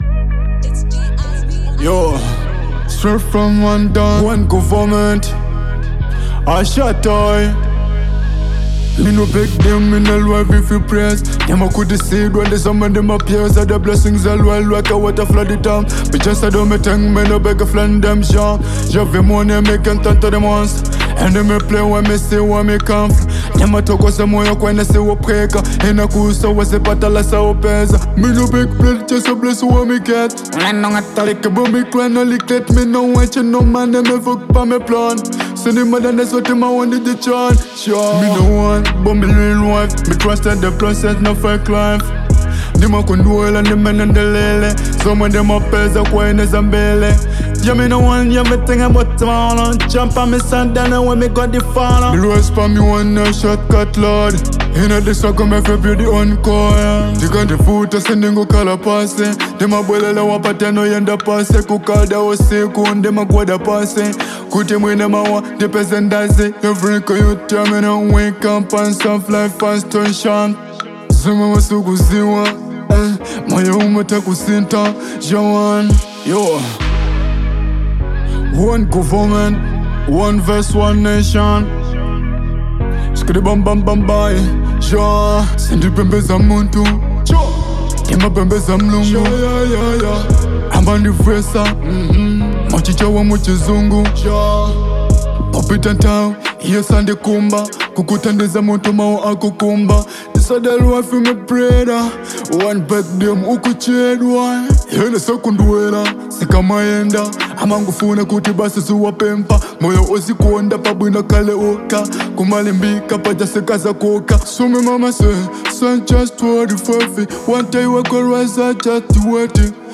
Dancehall • 2025-07-11